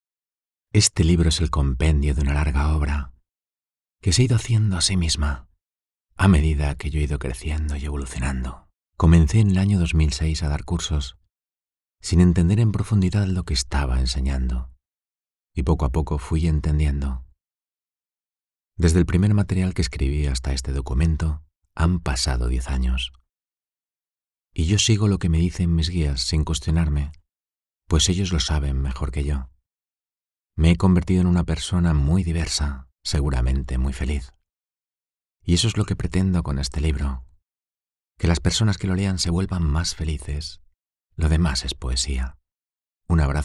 Livres audio
Voz neutral, emotiva, energética y divertida
Profesional Studio at home